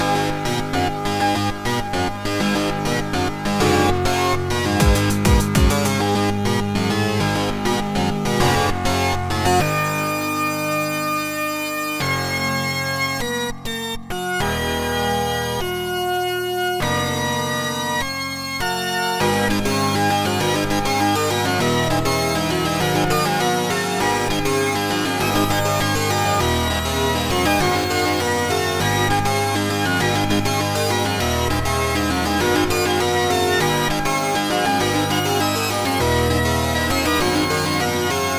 A small combatish piece made using some previously used melodies.